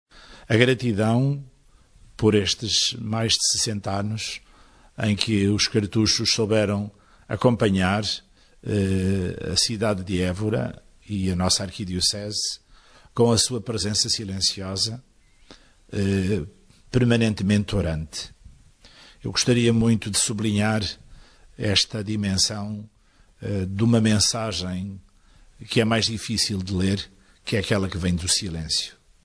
Évora, 04 out 2019 (Ecclesia) – O arcebispo de Évora, D. Francisco Senra Coelho, afirmou a sua gratidão pela comunidade da Cartuxa de Évora e deseja que a congregação se revigore e um dia possa voltar.